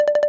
checkbox_mixed.wav